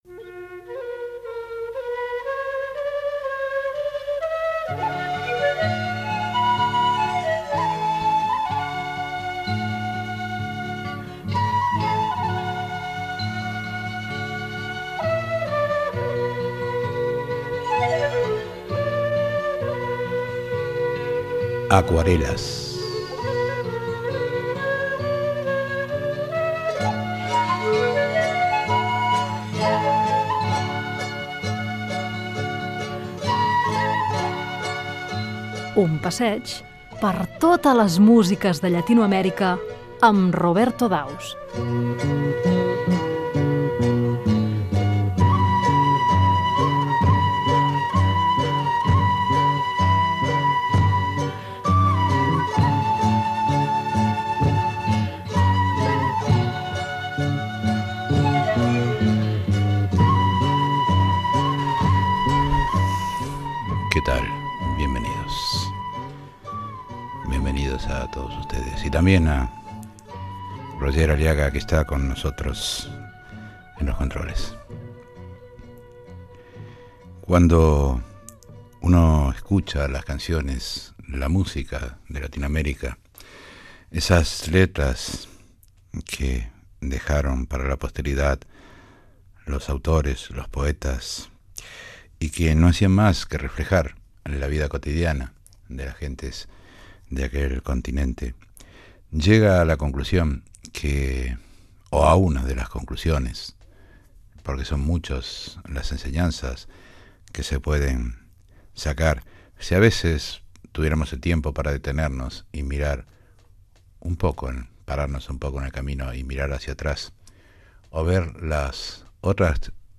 Careta i inici del programa
Divulgació